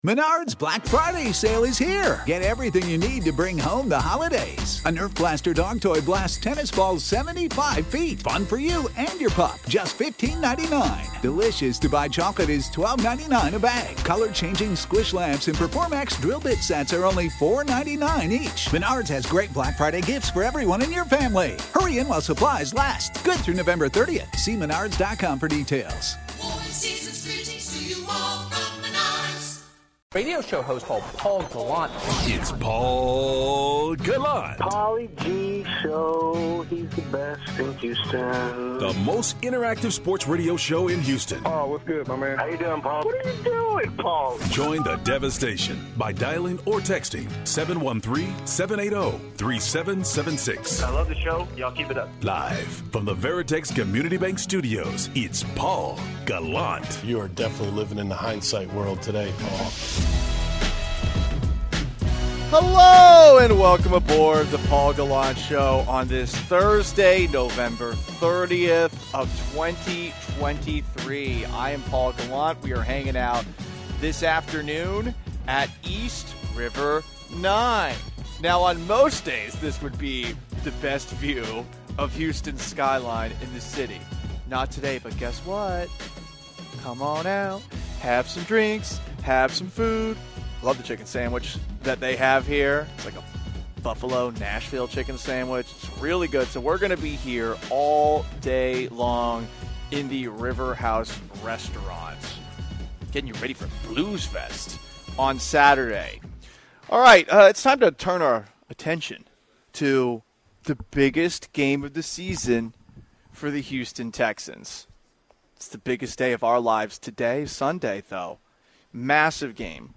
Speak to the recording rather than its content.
LIVE from East River 9!